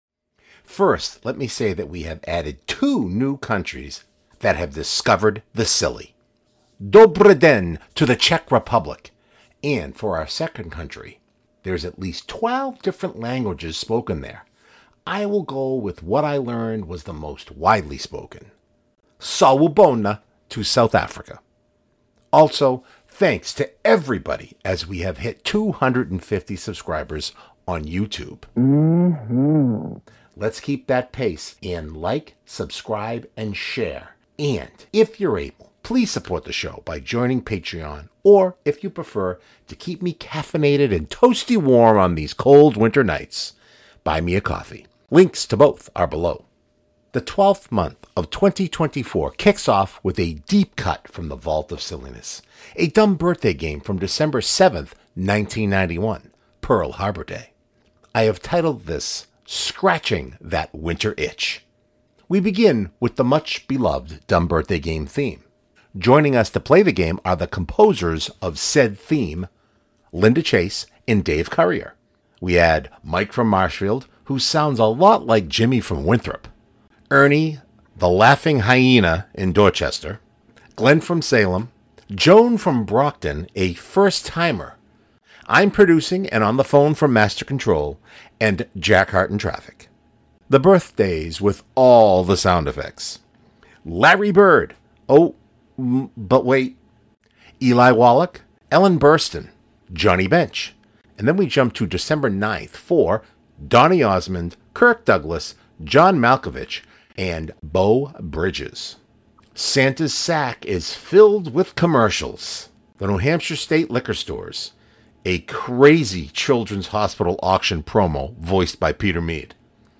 I have titled this: Scratching that Winter Itch We begin with the much beloved DBG Theme.